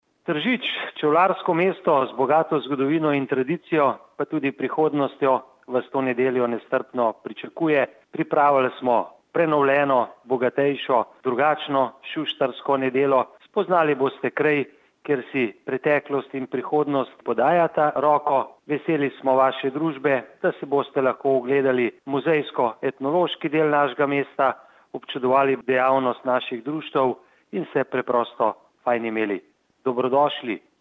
izjava_mag.borutsajoviczupanobcinetrzic_sustarska1.mp3 (746kB)